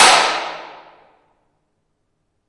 混凝土室 - 声音 - 淘声网 - 免费音效素材资源|视频游戏配乐下载
这是我居住的建筑物内的地下室的免费录音:)